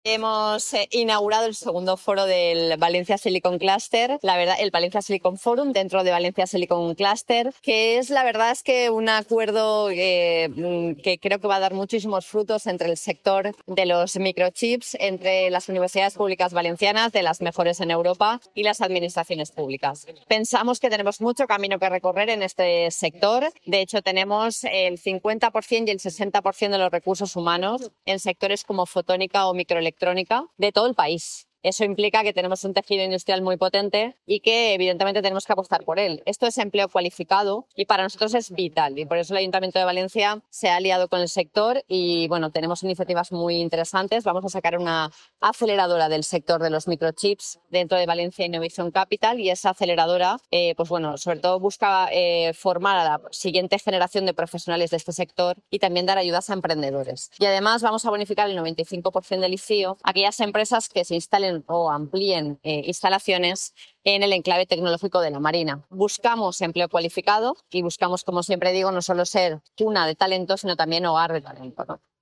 • La alcaldesa, en la apertura de la segunda edición de València Silicon Forum.